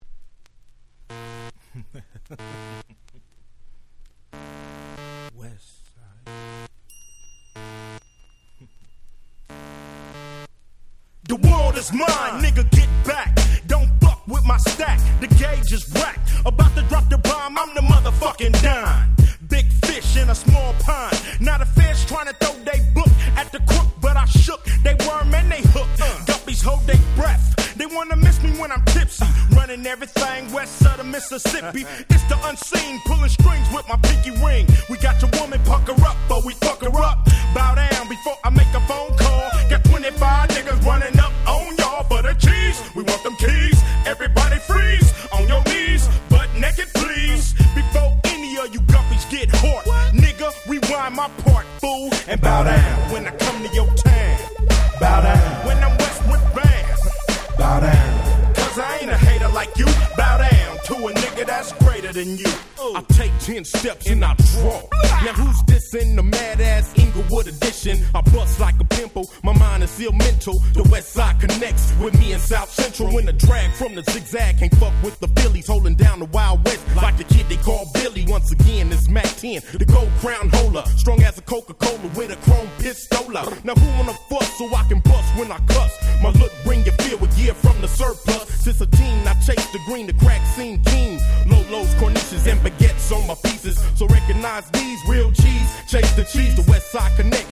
96' Smash Hit West Coast Hip Hop !!
めっちゃバンギン！！